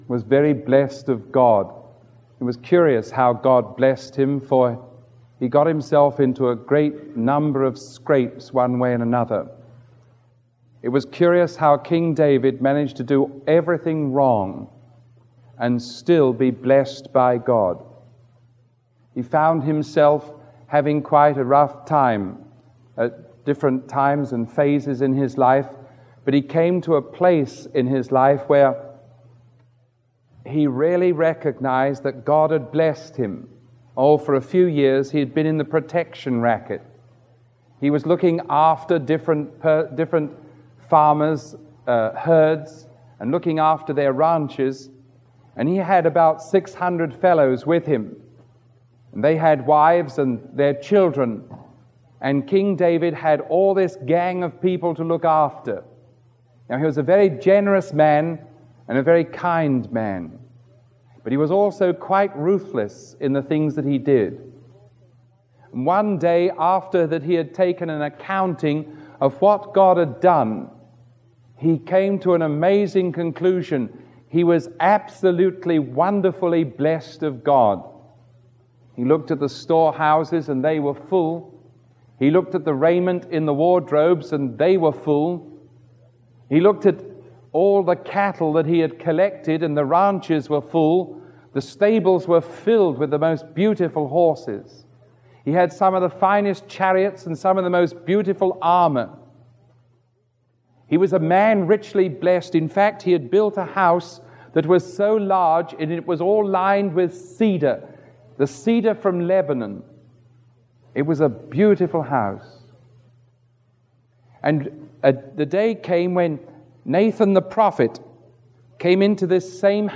Sermon 0432A recorded on October 11